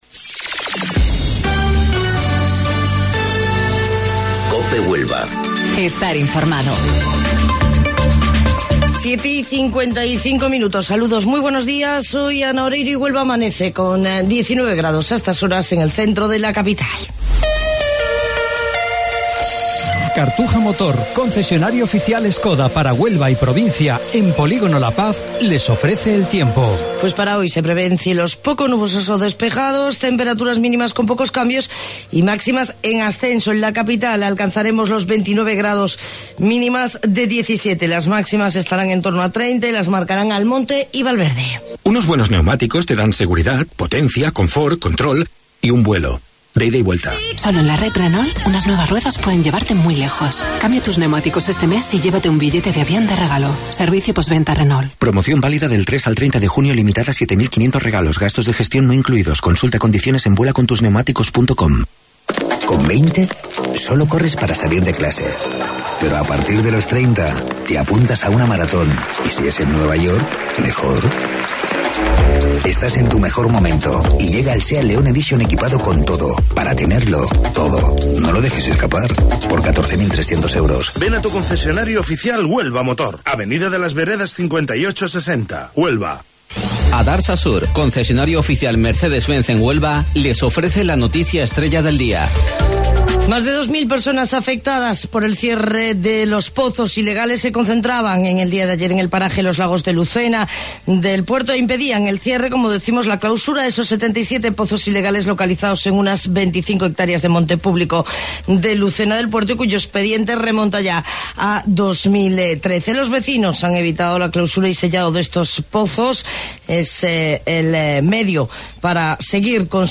AUDIO: Informativo Local 07:55 del 25 de Junio